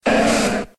Cri d'Ortide dans Pokémon X et Y.